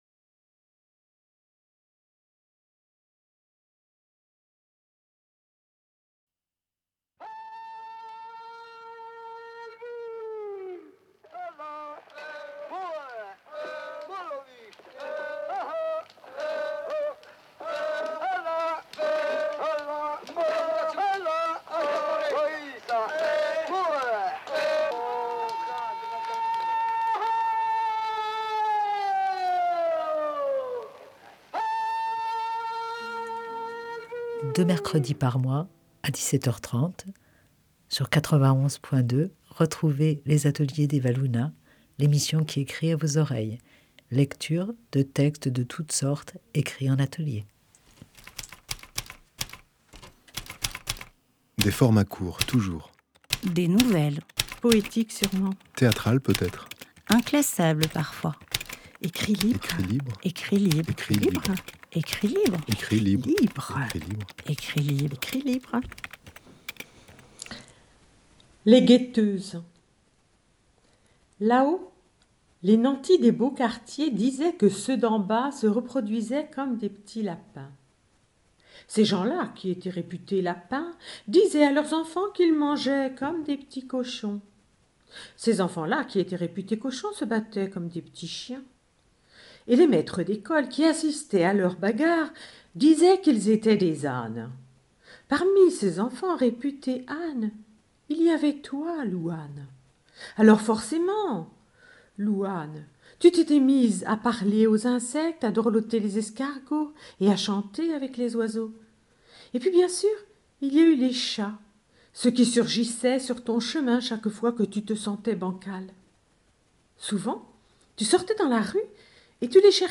Lectures de nouvelles où il est question de rêveries...